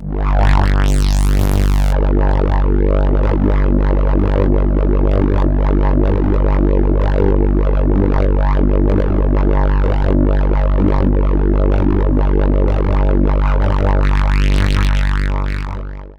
55-DIDJERI-L.wav